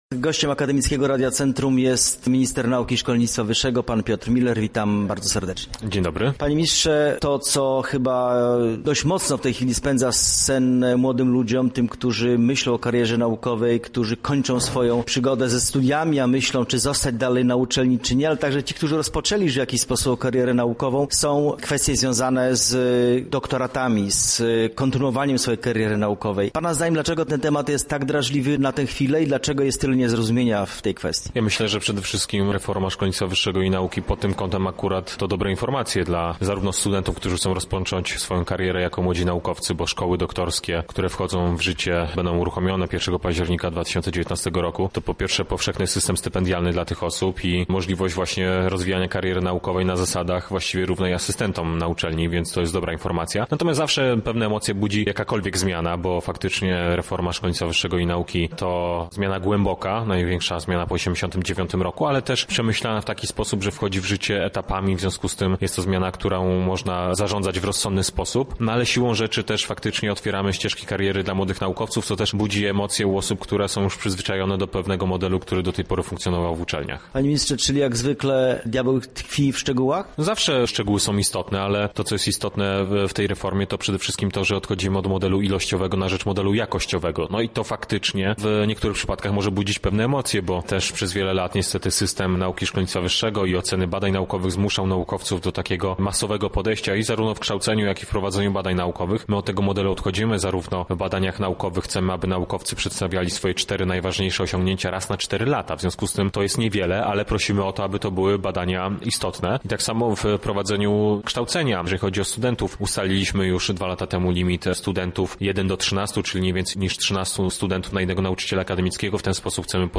Rozmowa z Wiceministrem Nauki i Szkolnictwa Wyższego na temat Konstytucji dla Nauki
Rozmowa » Czytaj dalej